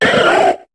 Cri de Phogleur dans Pokémon HOME.